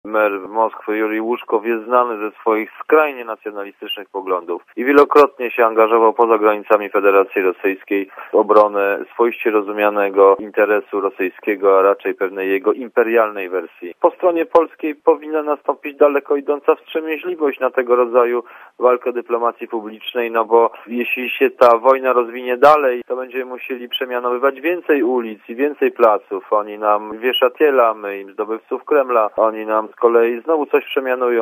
Wygląda na to, że ta kontrowersyjna decyzja władz Warszawy sprowokowała publiczny konflikt - powiedział Radiu Zet Bartłomiej Sienkiewicz, publicysta i ekspert ds. wschodnich.
* Mówi Barłomiej Sienkiewicz*